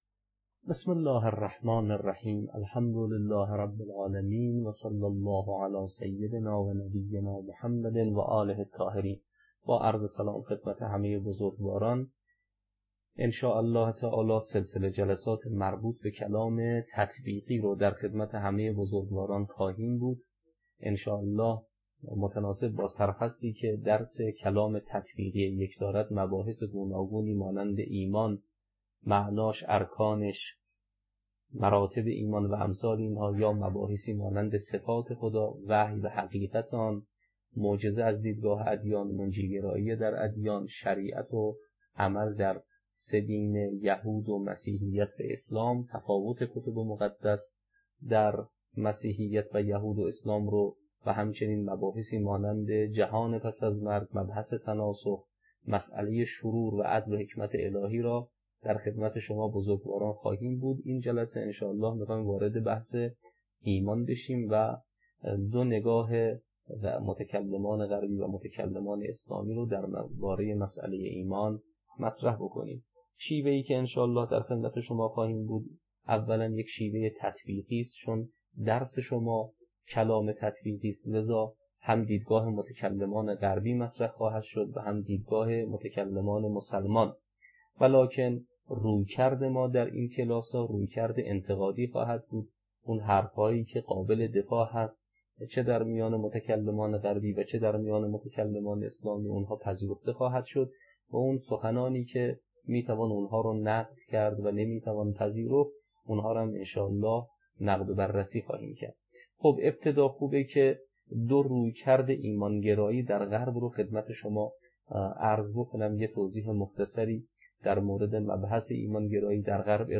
تدریس کلام تطبیقی